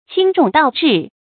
注音：ㄑㄧㄥ ㄓㄨㄙˋ ㄉㄠˋ ㄓㄧˋ
輕重倒置的讀法